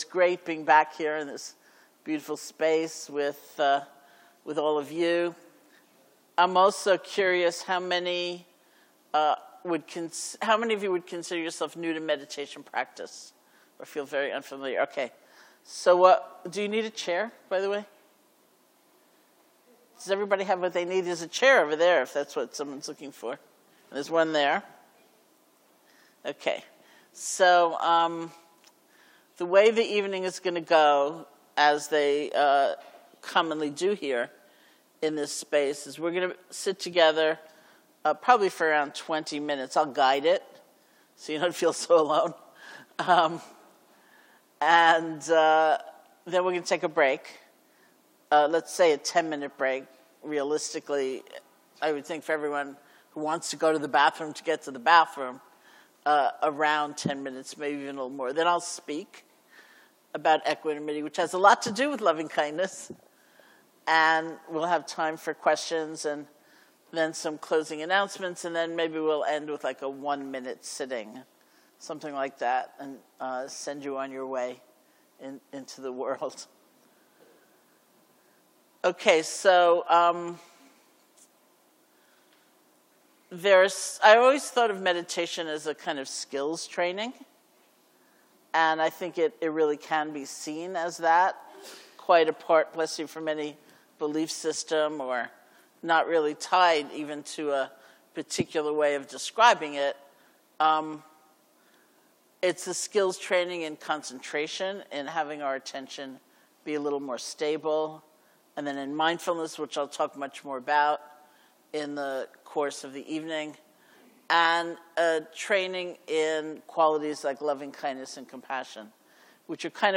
Equanimity for Challenging Times, Friday Nite Dharma talk
Offered by Sharon Salzberg at Seattle Insight Meditation Society.